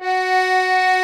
F#4 ACCORD-R.wav